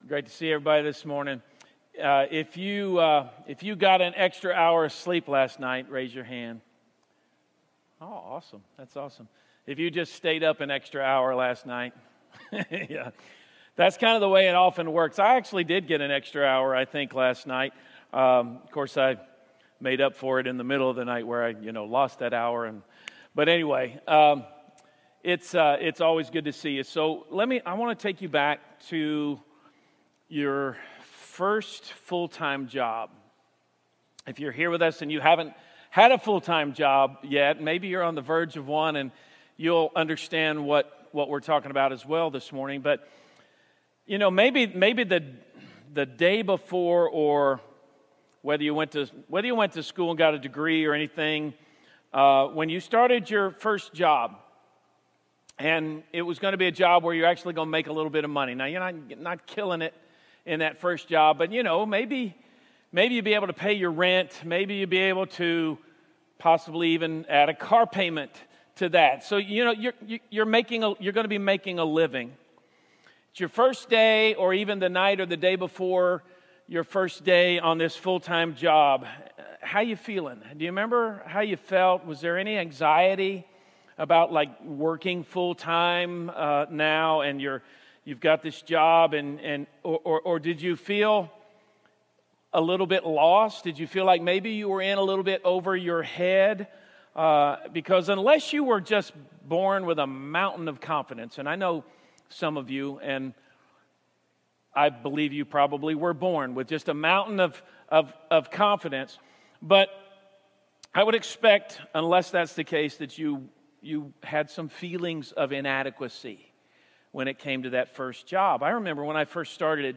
Sermons | Jefferson Christian Church